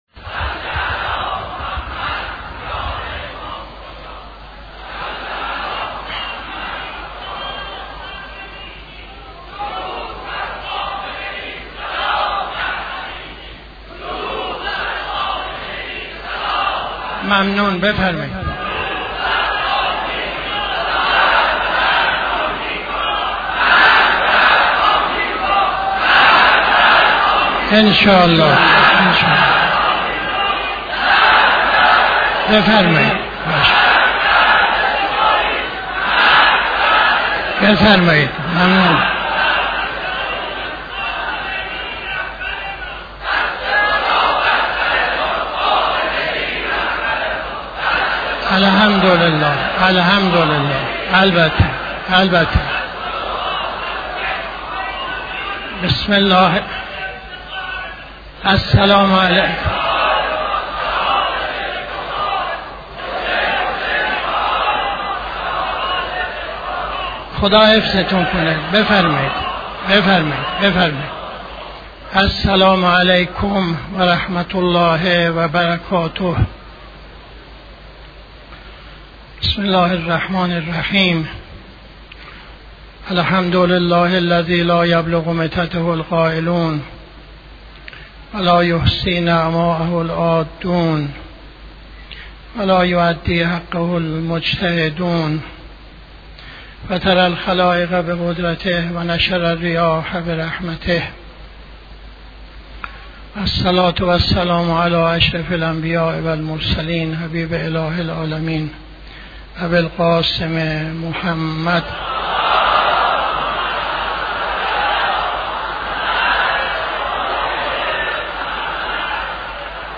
خطبه اول نماز جمعه 17-01-80